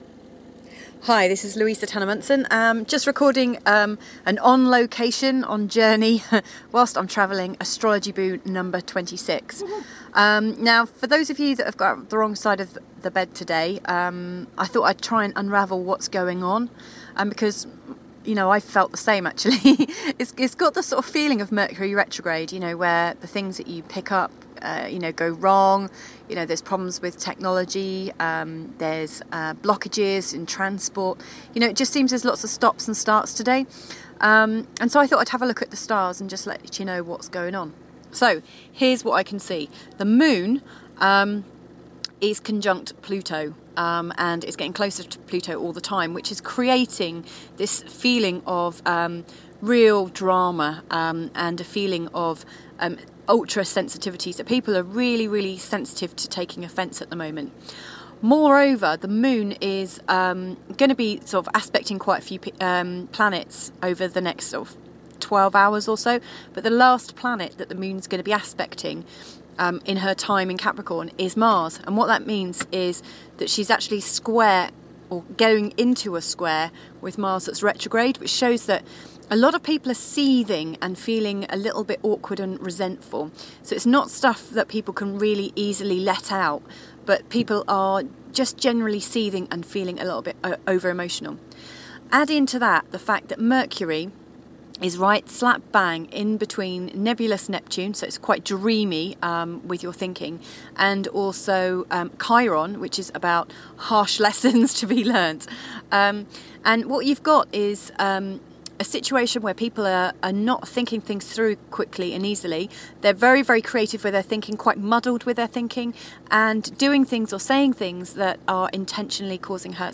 A remote quick update whilst we're on our travels! Today's astrology is grumpy, full of false starts and is (In places seething)!!!!!